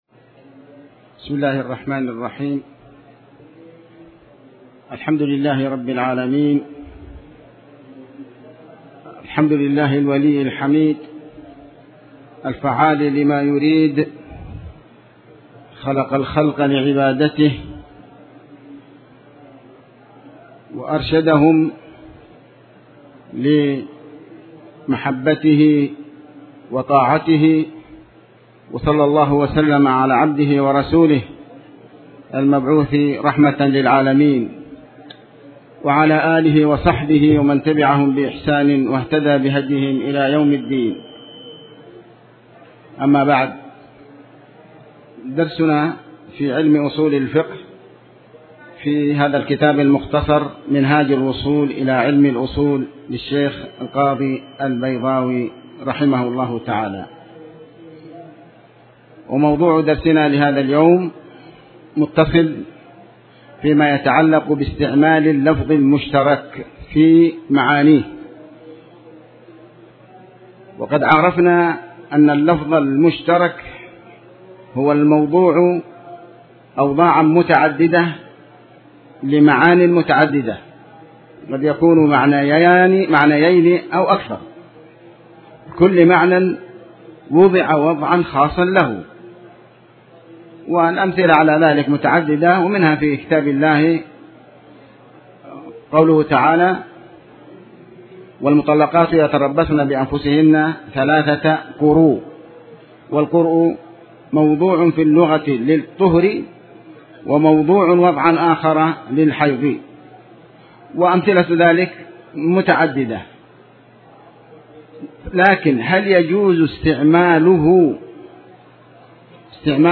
تاريخ النشر ٥ ذو القعدة ١٤٣٩ هـ المكان: المسجد الحرام الشيخ